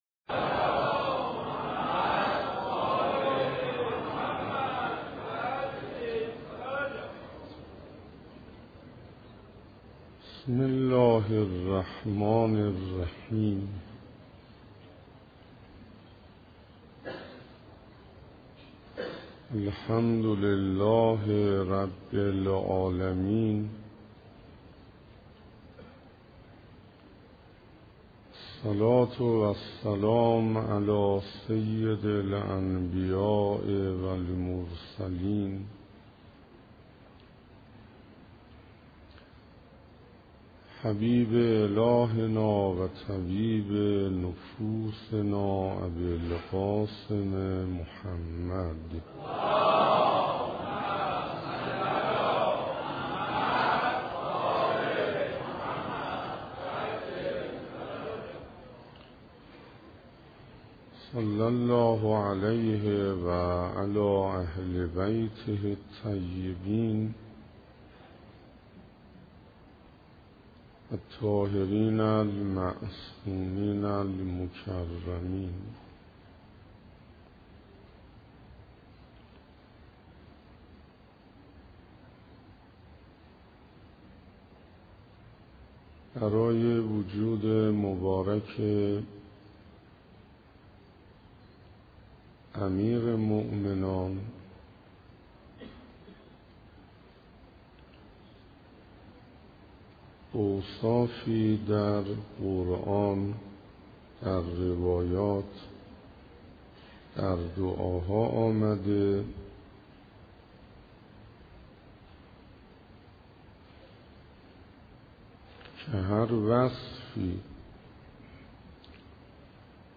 سخنرانی حجت الاسلام حسین انصاریان